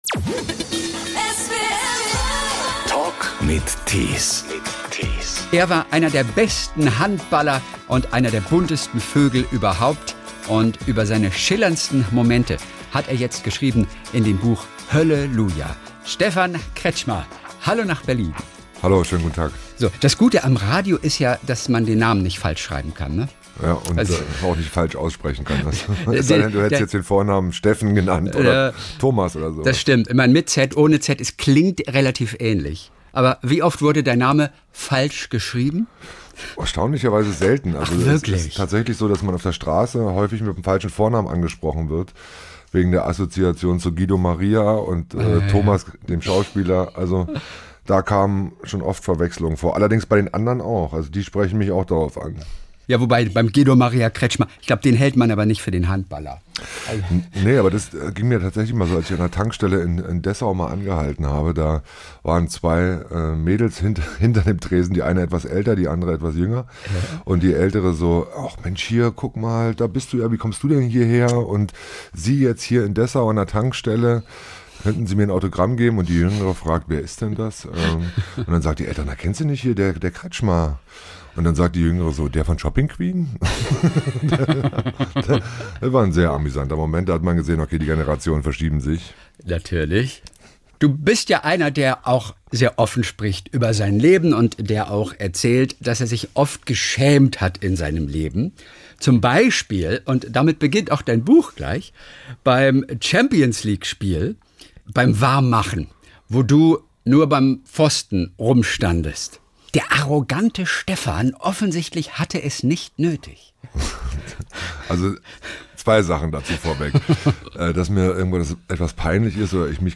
Der Talk in SWR3